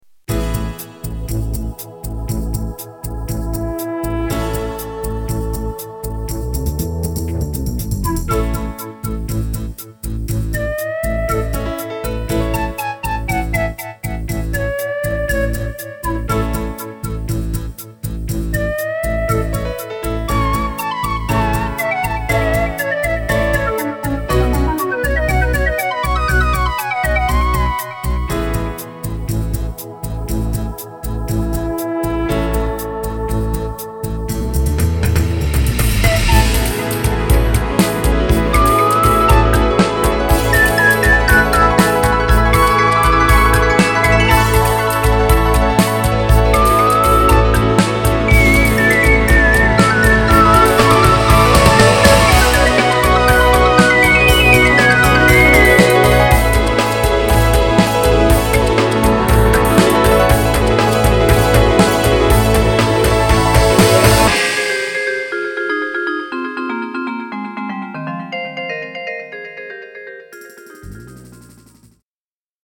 Simple Melody